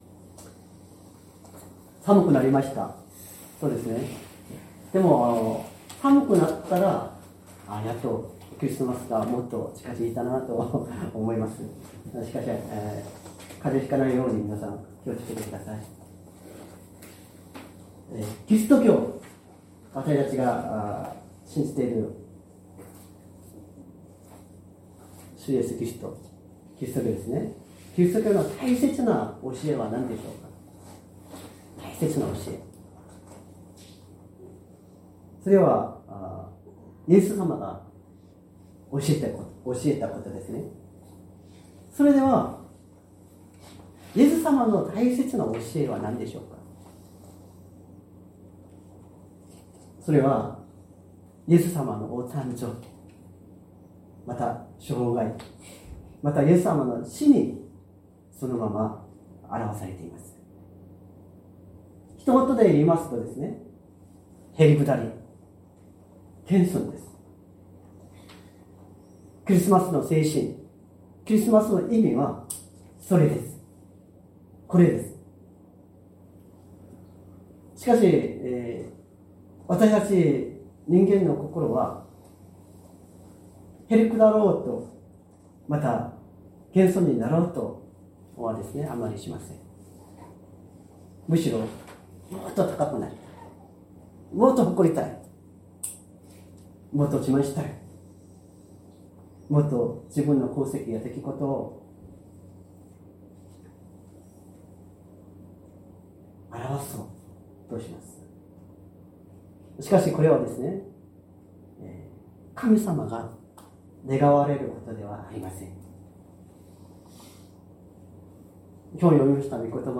善通寺教会。説教アーカイブ 2024年12月08日朝の礼拝「人として来られたキリスト」
音声ファイル 礼拝説教を録音した音声ファイルを公開しています。